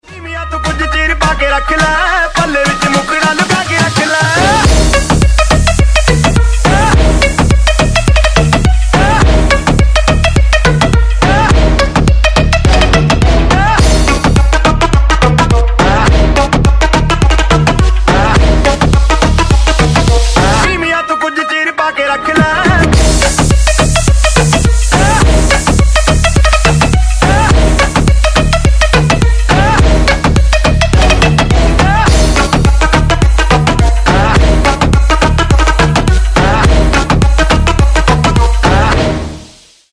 Громкие Рингтоны С Басами
Рингтоны Ремиксы » # Танцевальные Рингтоны